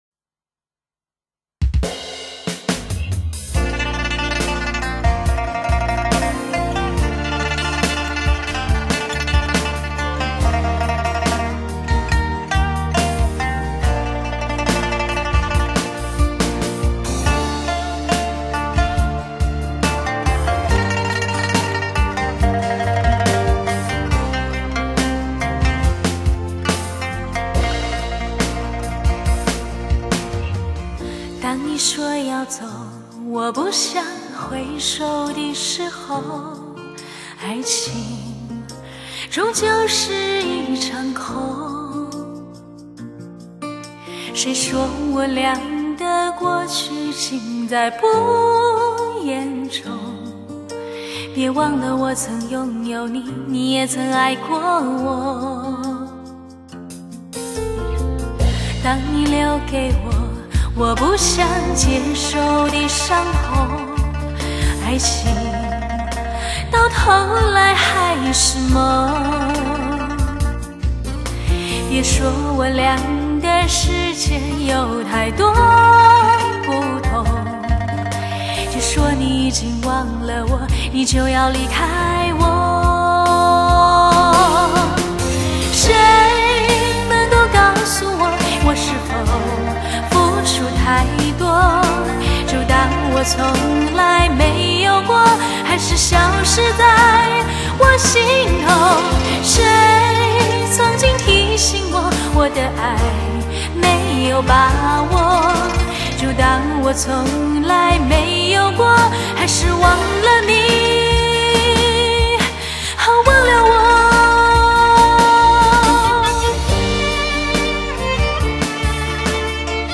[现代都市疗伤情歌系列]
最具HI-FI特质，最具情感纠葛的现代都市情歌系列